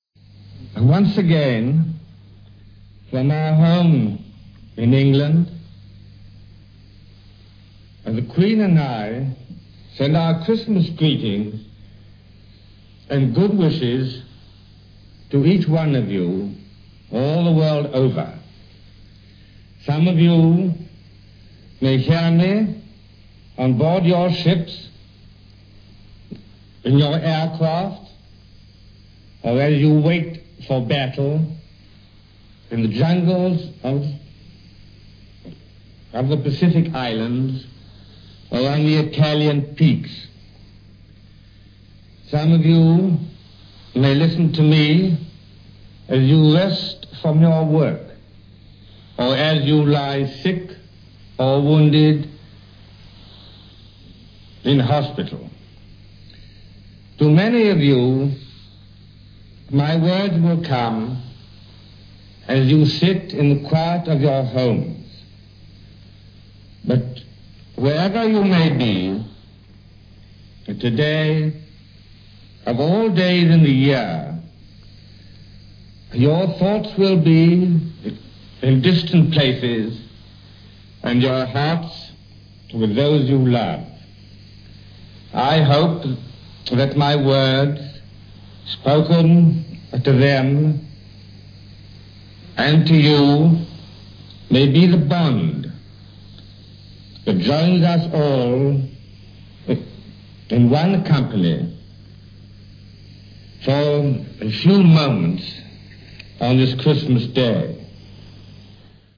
OTR Christmas Shows - Christmas Speech - 1943-12-25 BBC King George